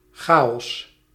Ääntäminen
IPA: /ka.o/